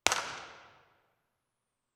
Room Impulse Response of a 9600 seat Auditorium
The low Q source is a dodecahedron.
File Type: XY Cardioid Stereo
Microphone: Core Sound Tetramic
Source: 14 sec Log Sweep
Low-Q Sample
IR_TP2_Dodec_60ft.wav